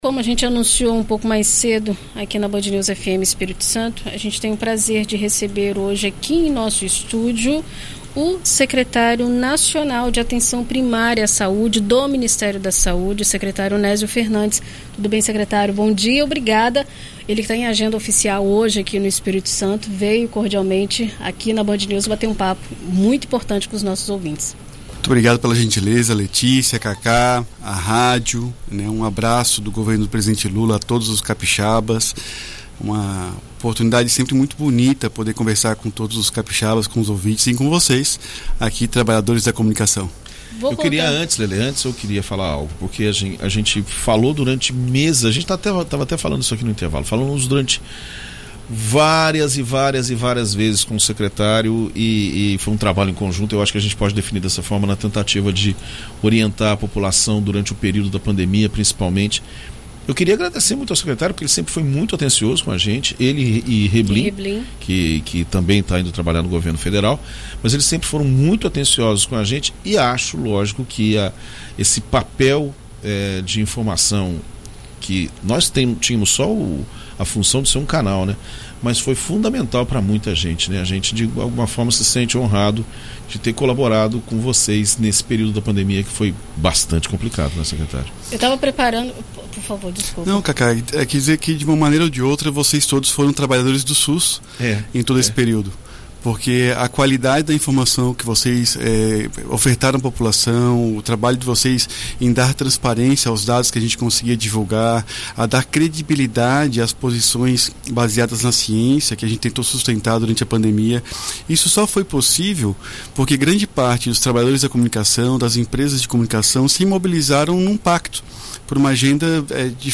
Em entrevista à BandNews FM Espírito Santo nesta quinta-feira (04), o Secretário de Atenção Primária à Saúde (Saps) do Ministério da Saúde, Nésio Fernandes, fala sobre as novidades e ações realizadas pelo Mais Médicos.